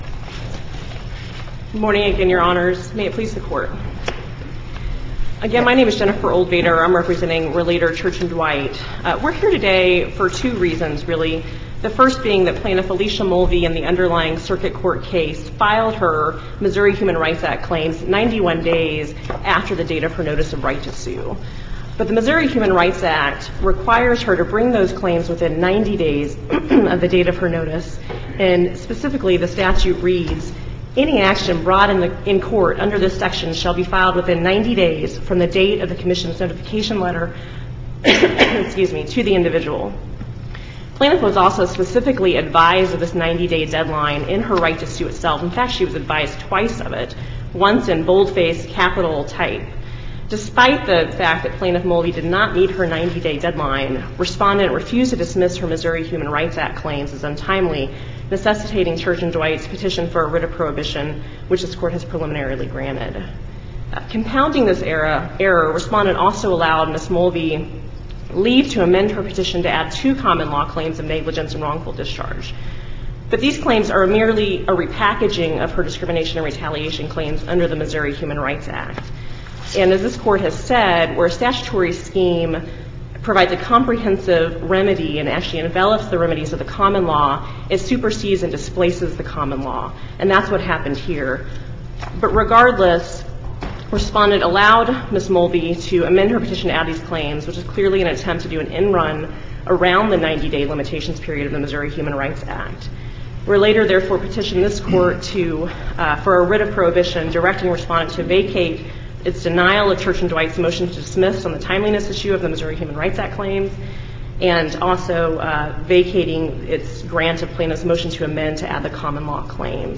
MP3 audio file of arguments in SC95976